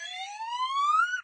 slide_whistle_up.ogg